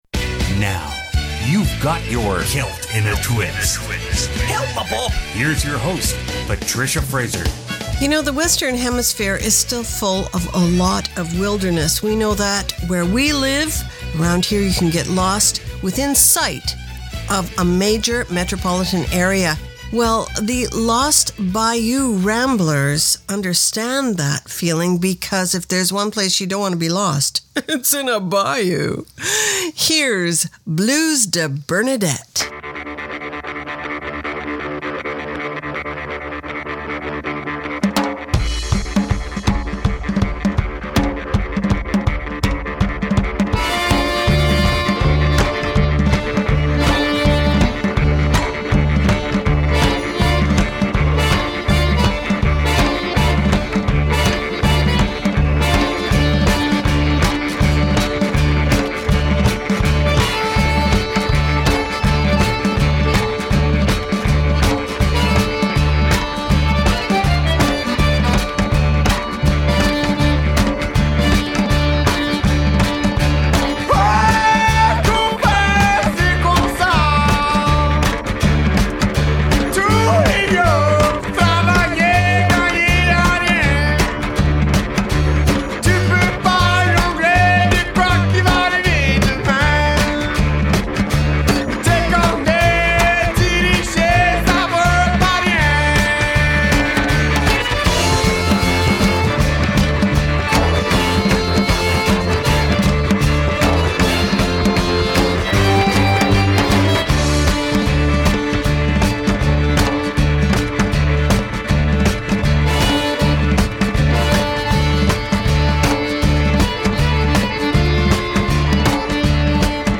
Canada's Conemporary Celtic Radio Hour
File Information Listen (h:mm:ss) 0:58:38 Celt_In_A_Twist_September_27_2015 Download (11) Celt_In_A_Twist_September_27_2015.mp3 70,365k 160kbps Stereo Comments: A jam packed hour with 14 tracks of the best in contemporary Celtic.